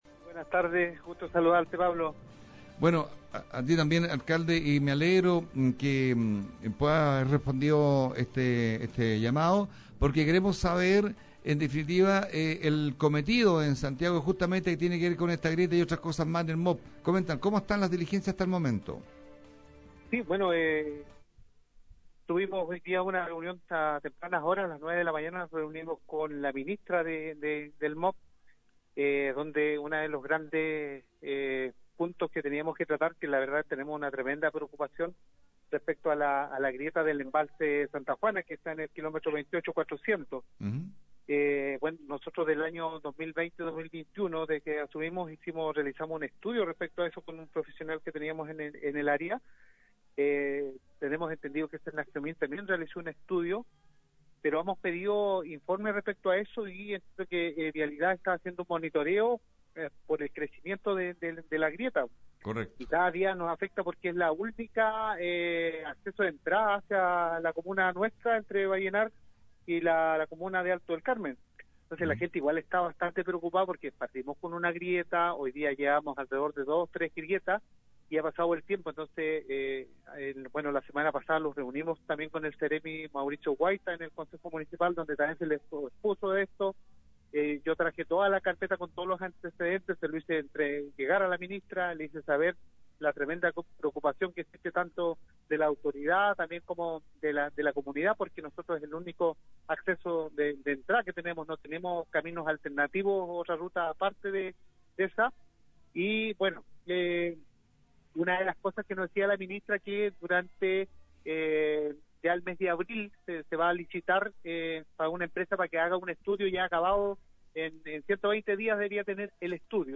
ENTREVISTAS-cristian-olivares-.mp3